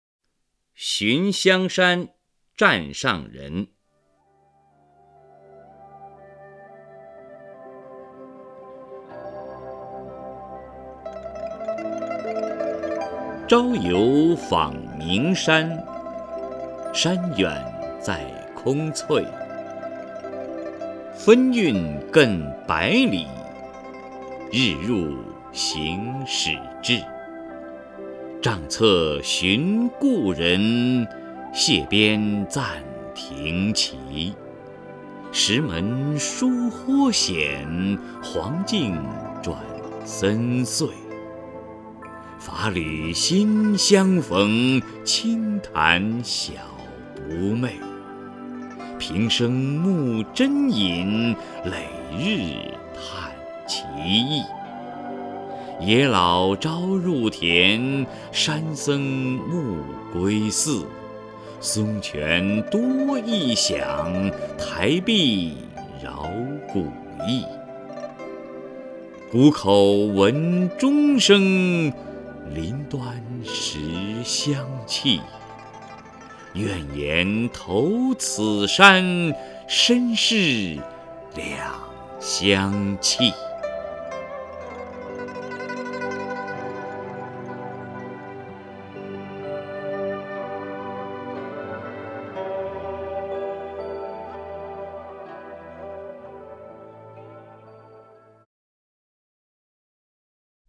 首页 视听 名家朗诵欣赏 王波
王波朗诵：《寻香山湛上人》(（唐）孟浩然)